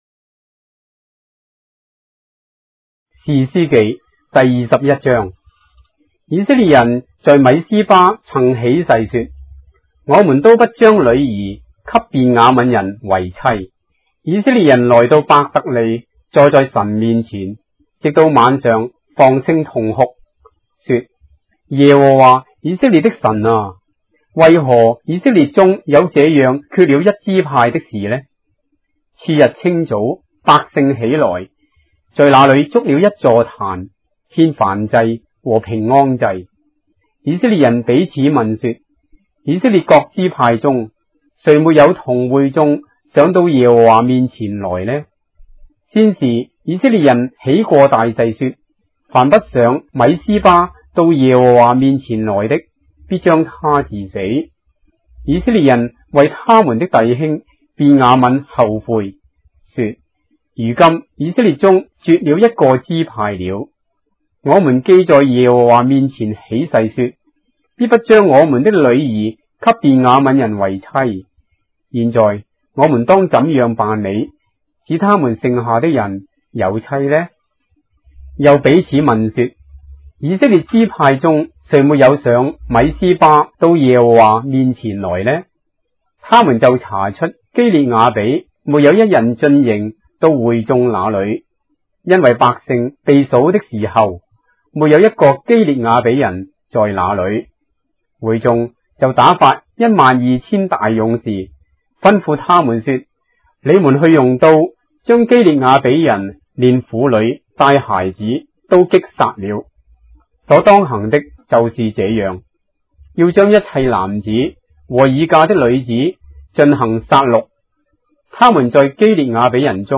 章的聖經在中國的語言，音頻旁白- Judges, chapter 21 of the Holy Bible in Traditional Chinese